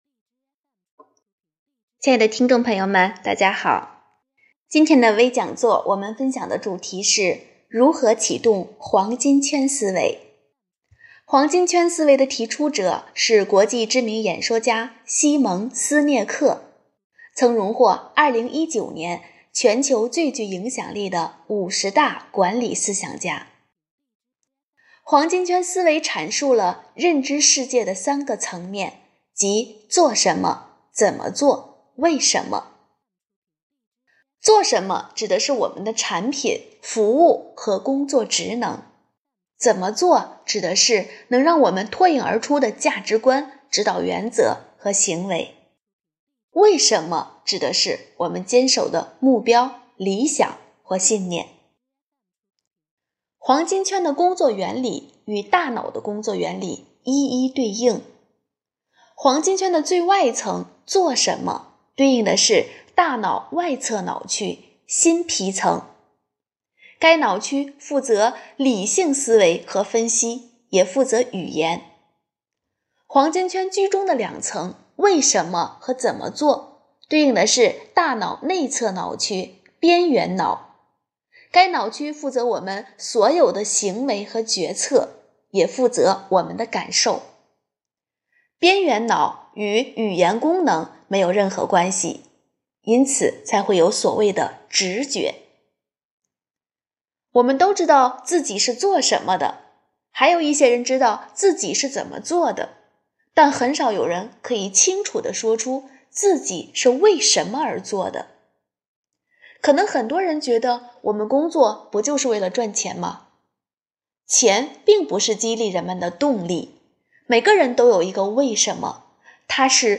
【讲座】丰图讲座 | 如何启动黄金圈思维
活动地点：丰南图书馆 线上活动